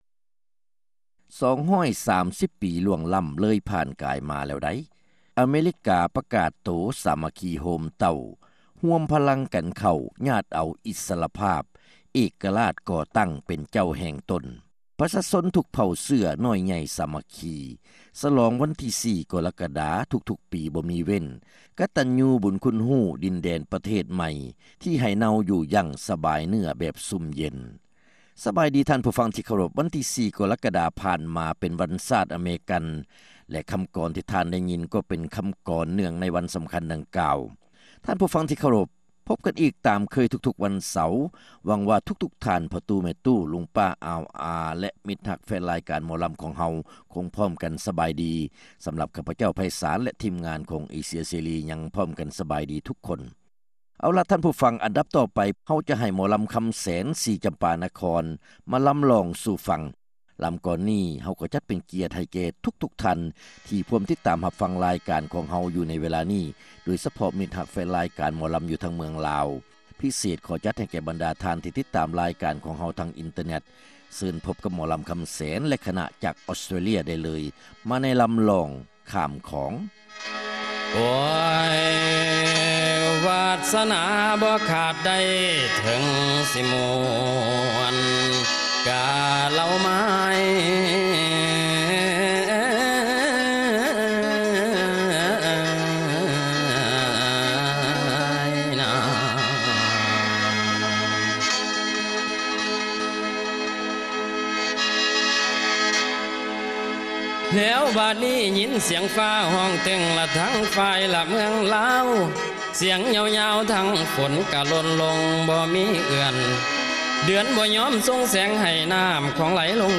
ຣາຍການໜໍລຳ ປະຈຳສັປະດາ ວັນທີ 7 ເດືອນ ກໍຣະກະດາ ປີ 2006